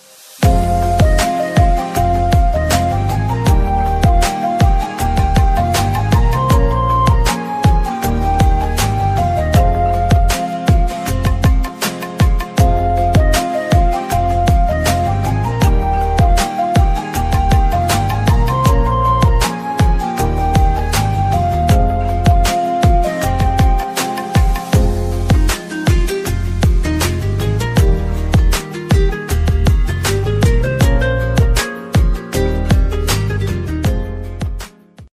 Instrumental Ringtones